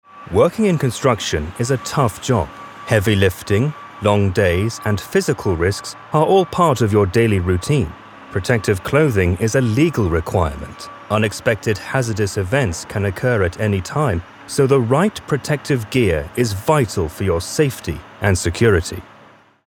Anglais (Britannique)
Profonde, Naturelle, Polyvalente, Fiable, Amicale
E-learning
Les clients ont décrit sa voix comme riche, résonnante, profonde, teintée de gravitas, très adaptable à différents genres et (presque) incontestablement britannique !